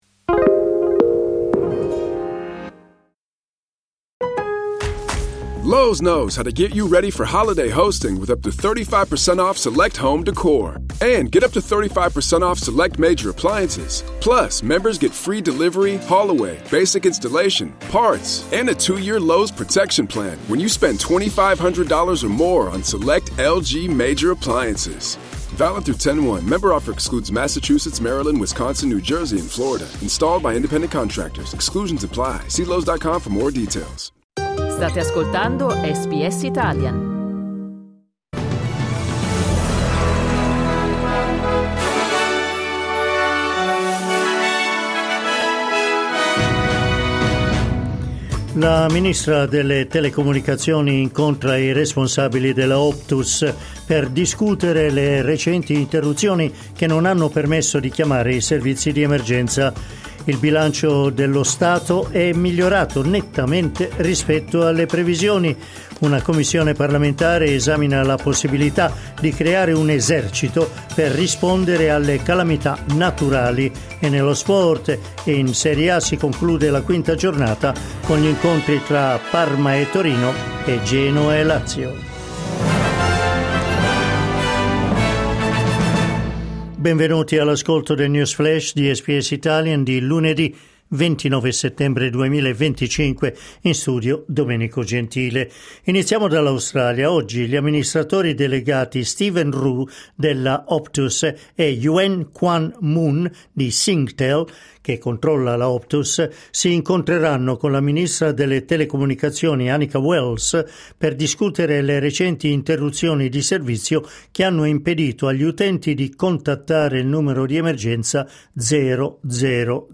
News flash lunedì 29 settembre 2025
L’aggiornamento delle notizie di SBS Italian.